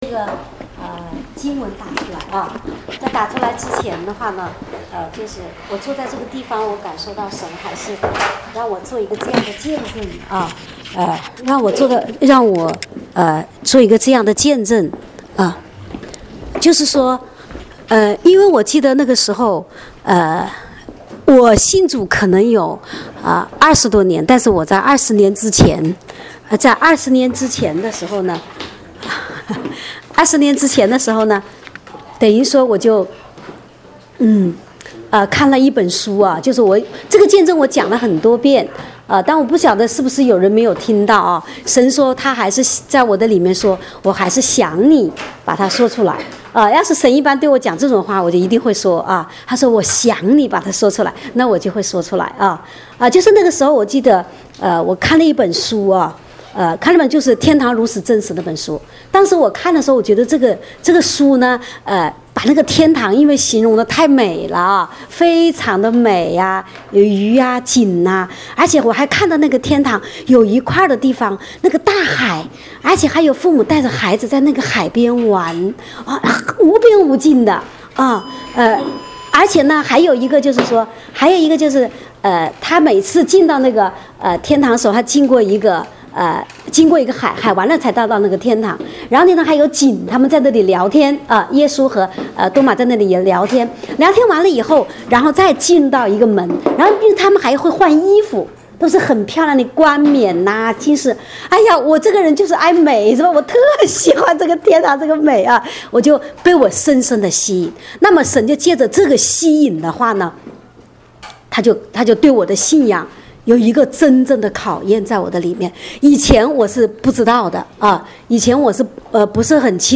正在播放：--主日恩膏聚会录音（2014-10-05）